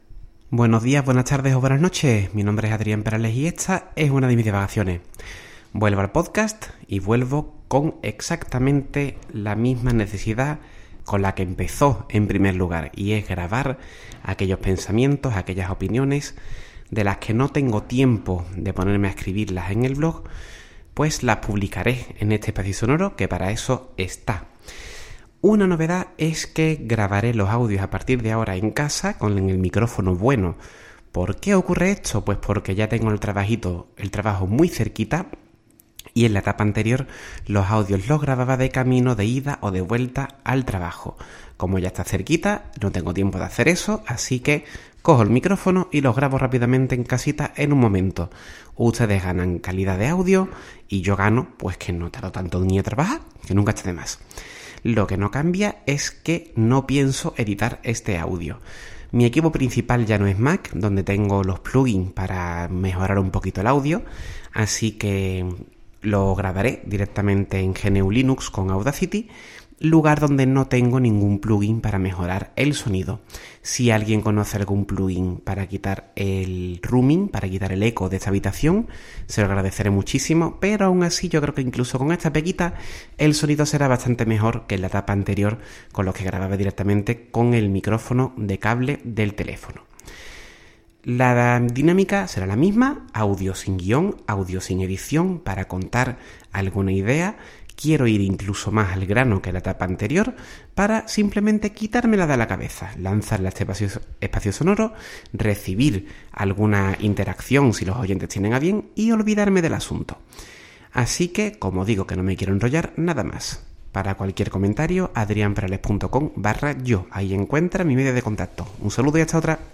Ahora grabo en casa.